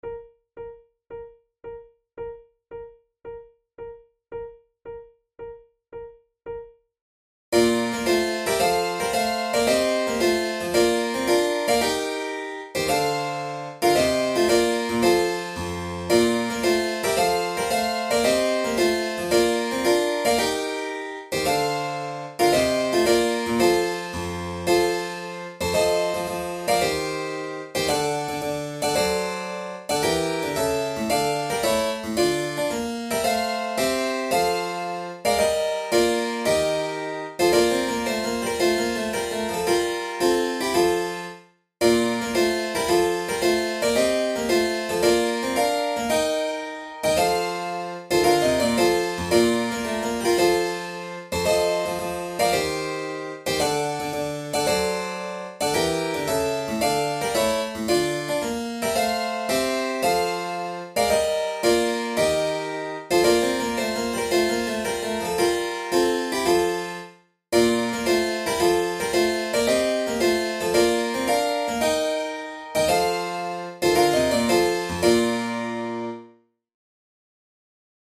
arranged for flute and keyboard
Categories: Baroque Difficulty: intermediate